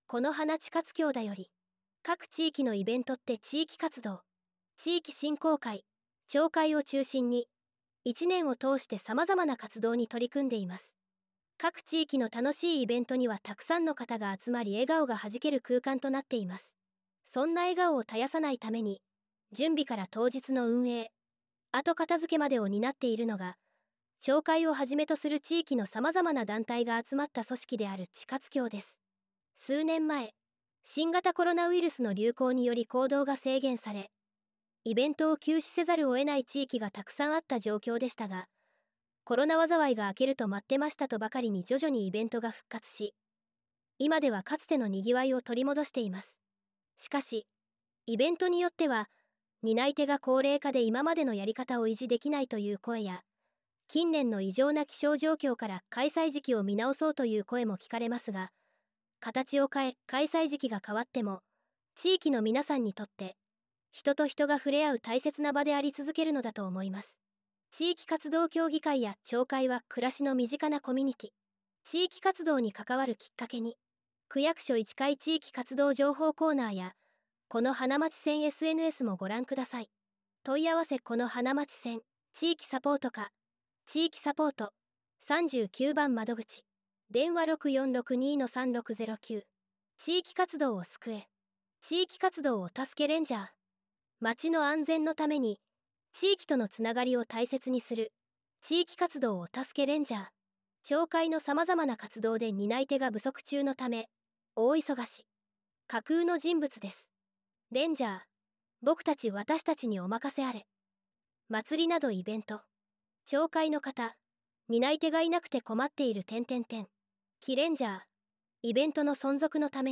音声版　広報「このはな」令和7年7月号